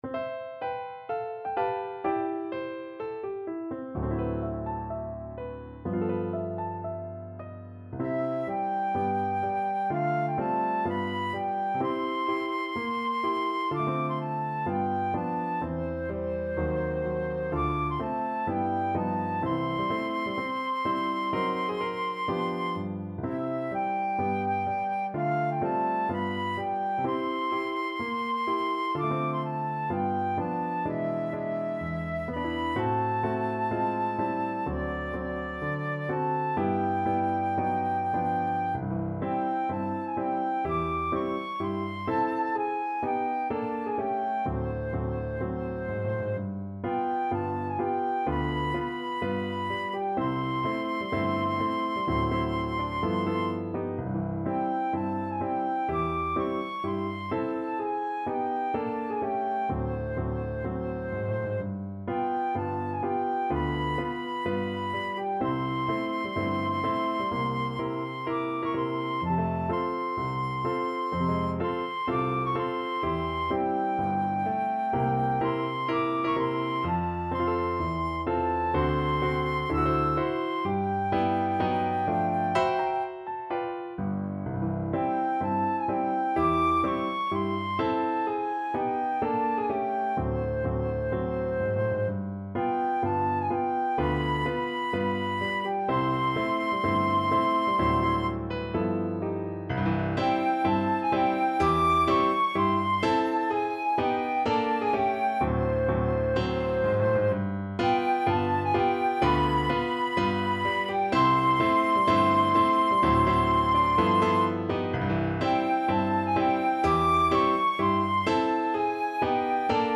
Flute version
2/2 (View more 2/2 Music)
Moderato cantabile =126